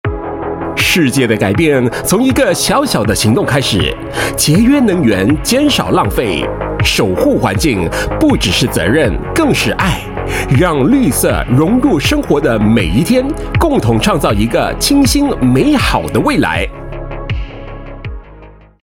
Voice Samples: PSA
male